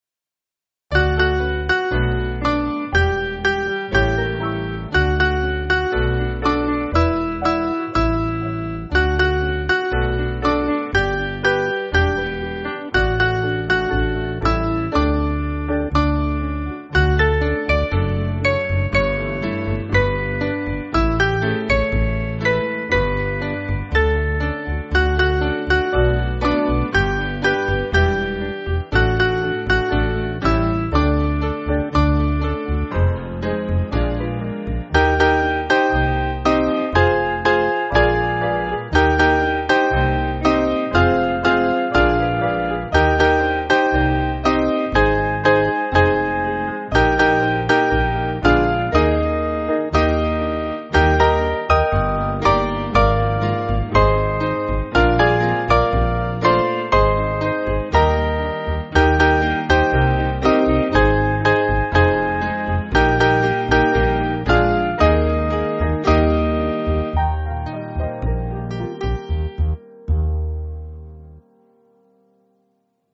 Mainly Piano
(CM)   2/D-Eb